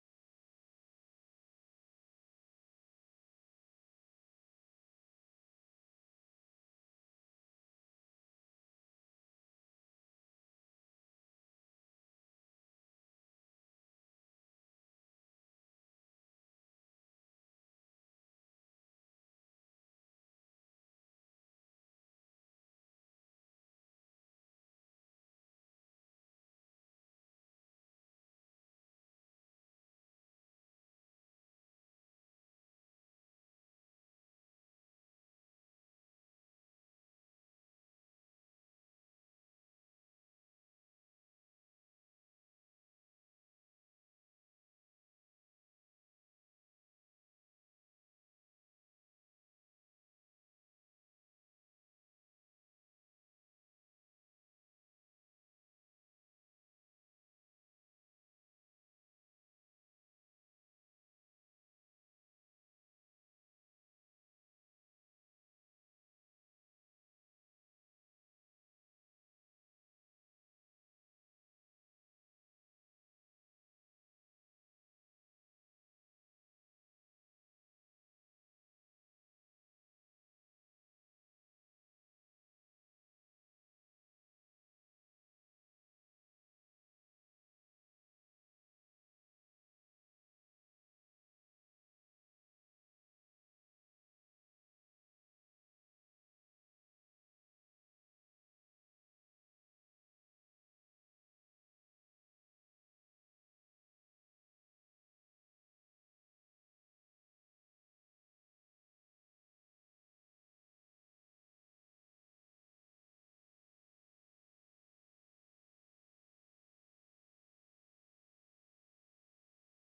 Special Guest Speaker